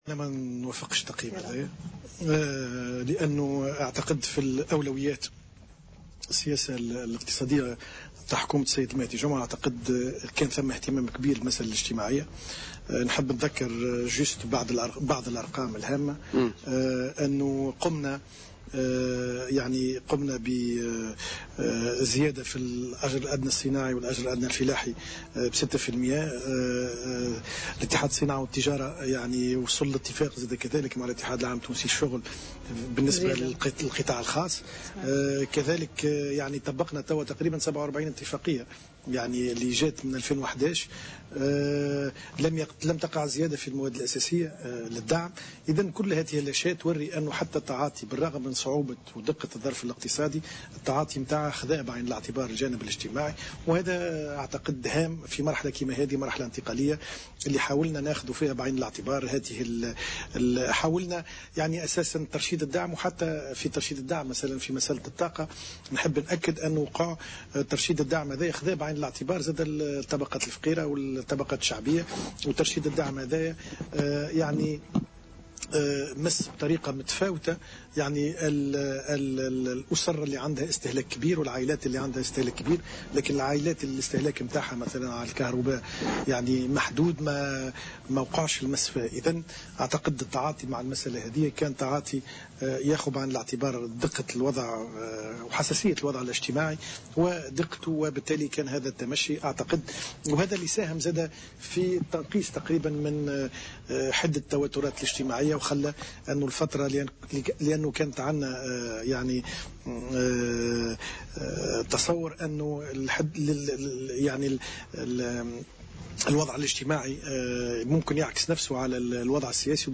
Invité de Politica dans une émission spéciale aux Journées de l’Entreprise qui se tient les 5 et 6 décembre à El Kantaoui, Ben Hammouda a rappelé que le gouvernement actuel a réussi à arrêter l’hémorragie subie par les finances publiques, en limitant déficit budgétaire à 6% en 2014 qui aurait pu atteindre 9 %, si le gouvernement n’avait pas prix les mesures nécessaires.